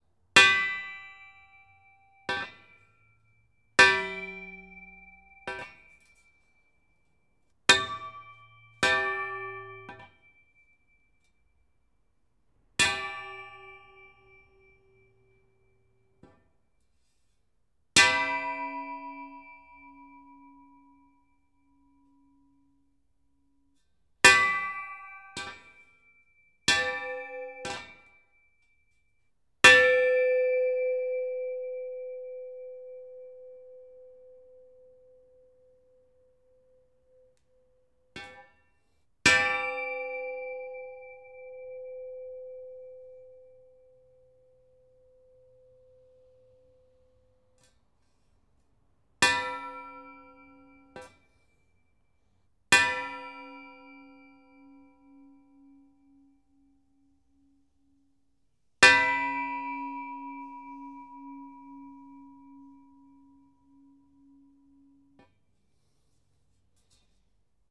Light_Steel_Pipe_On_Concrete_01
clang clank ding drop hit impact industrial metal sound effect free sound royalty free Sound Effects